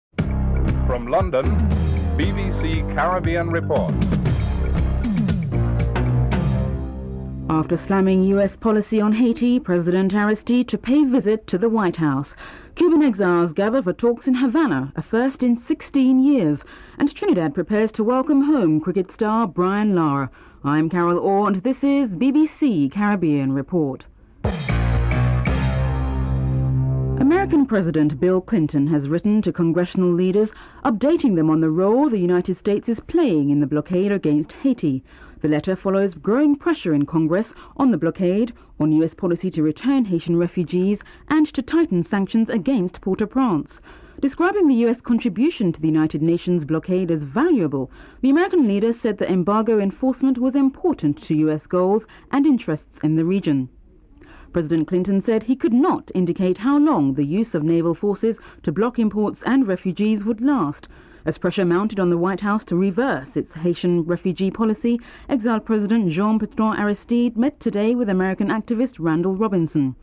Recap of the headlines and theme music (12:47-13:13)